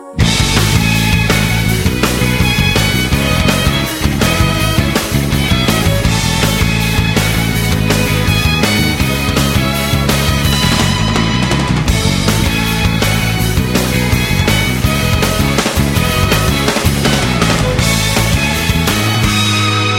Проигрыш